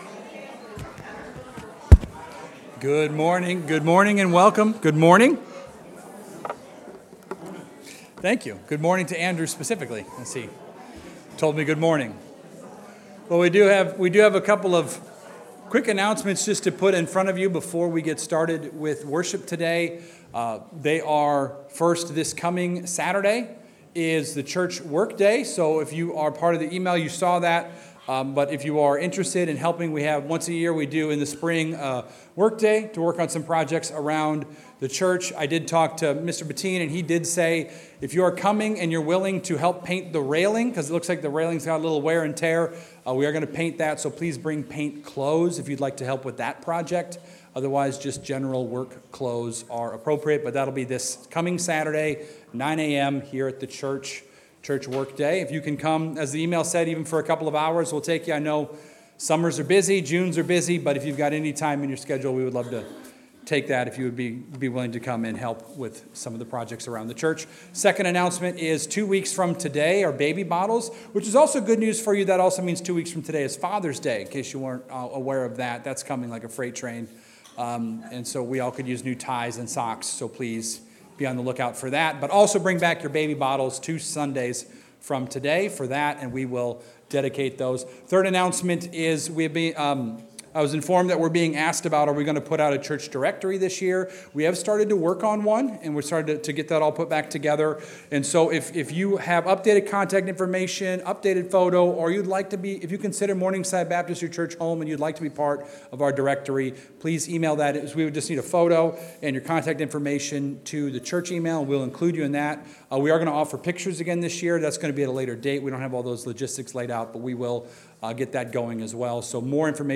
1 IA X Fresh Clinics: The State of Medical Aesthetics #1 1:06:23 Play Pause 5d ago 1:06:23 Play Pause Play later Play later Lists Like Liked 1:06:23 This is the first chapter of a new mini-series of bonus episodes in collaboration with Fresh Clinics. After 300 episodes we've decided to try something different - recording in a new studio, getting back to real life and in person recordings and to work with Fresh Clinics and some of their brand partners.